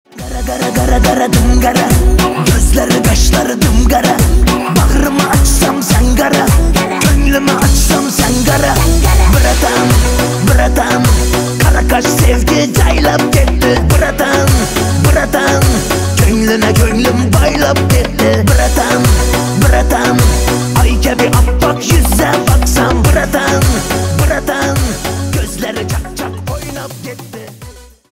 восточные на брата